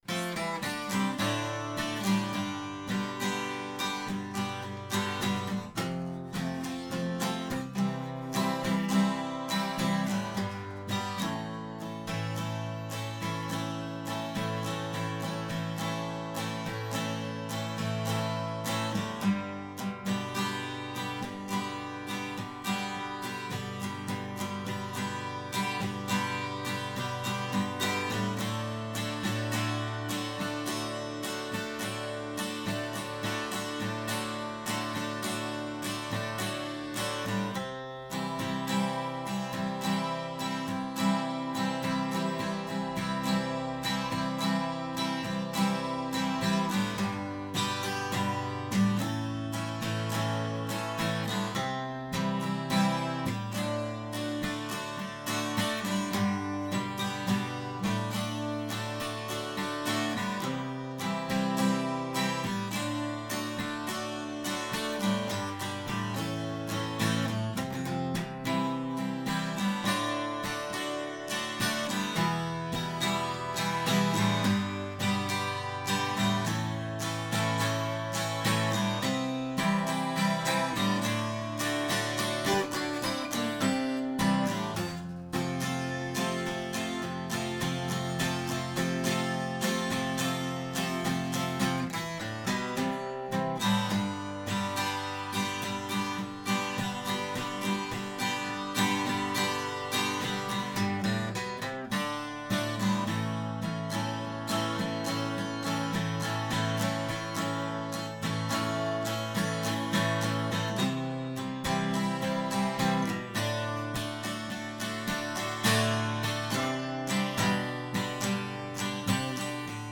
music_smorgasbord_liveinthismoment_acoustic.m4a